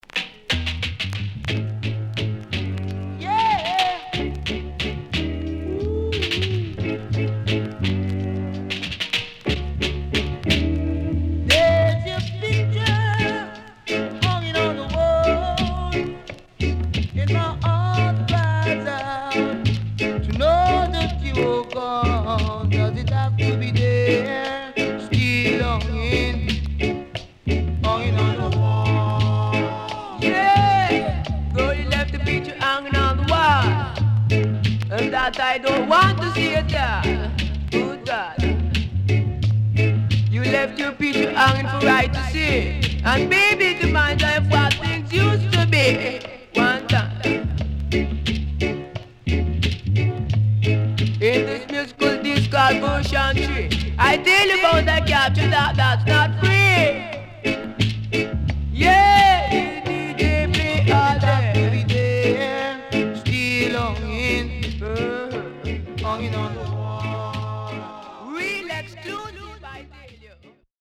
70’s DEEJAY
SIDE A:所々チリノイズがあり、少しプチパチノイズ入ります。盤面は所々うすいこまかい傷あり。